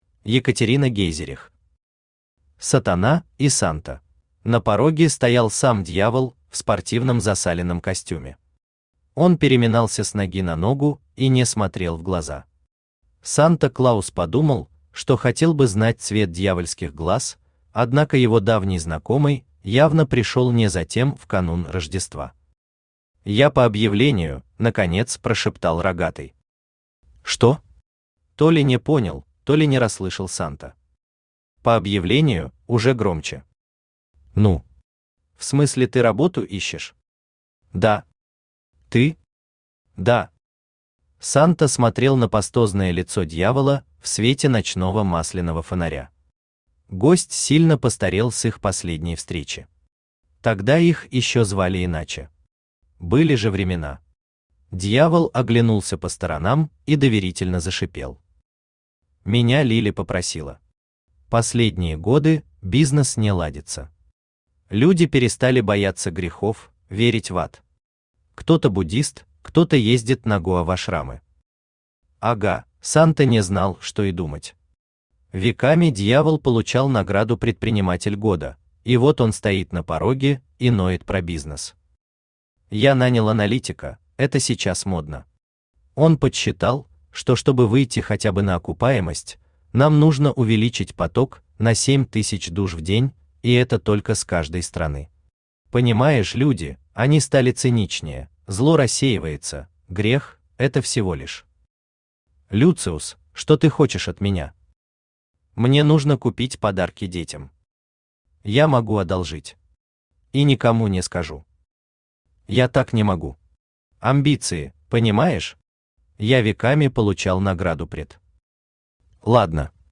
Аудиокнига Сатана и Санта | Библиотека аудиокниг
Aудиокнига Сатана и Санта Автор Екатерина Гейзерих Читает аудиокнигу Авточтец ЛитРес.